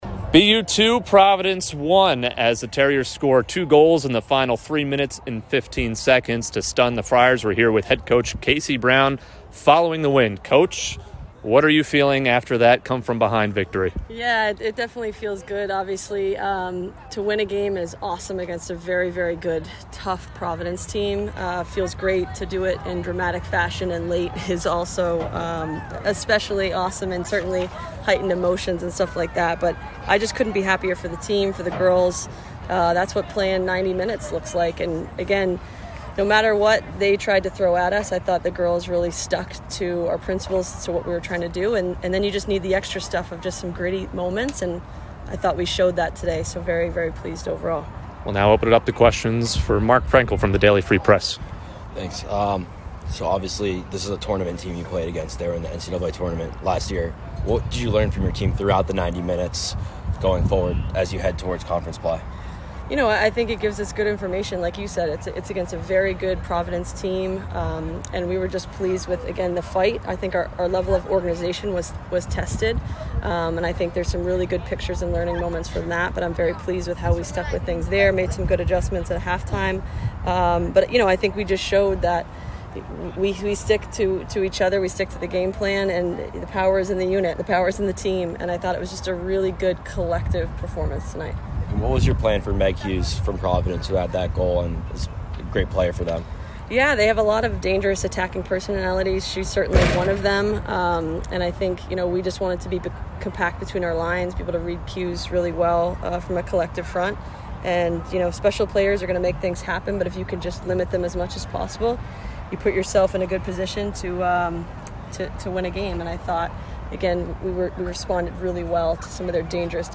Providence Postgame Interview